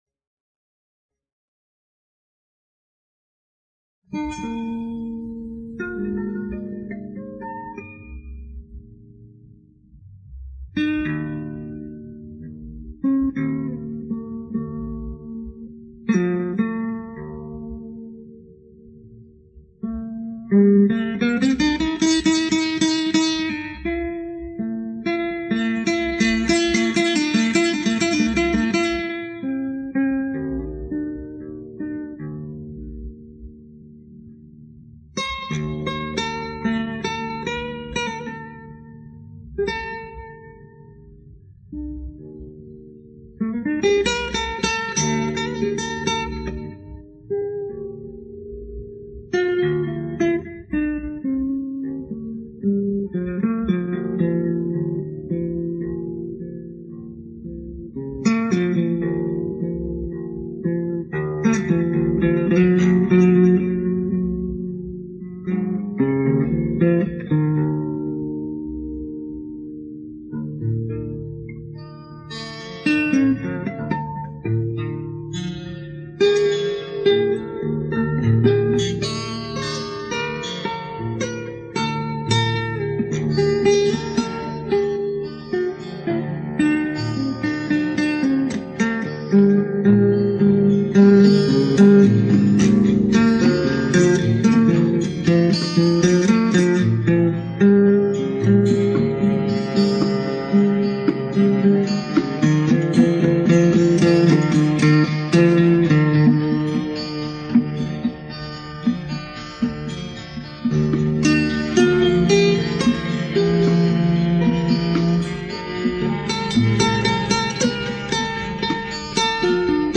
• Жанр: Джаз-рок
Инструментальная